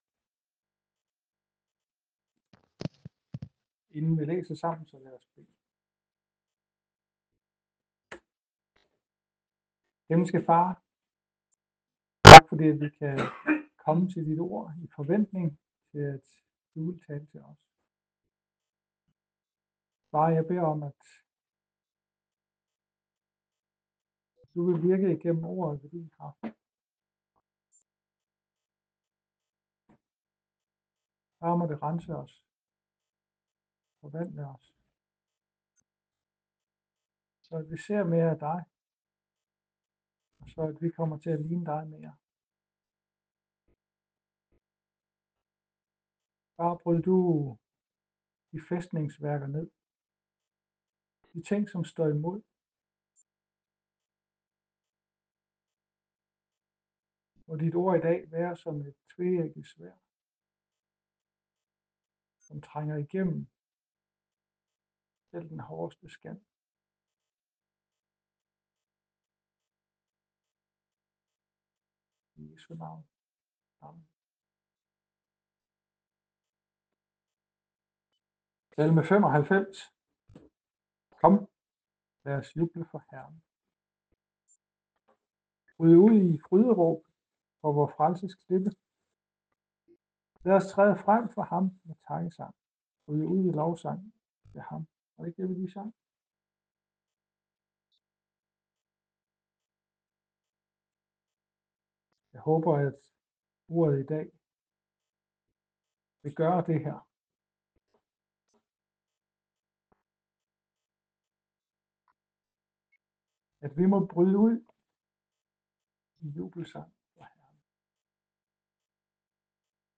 Nytårstale Joh. 7:37 Vågn op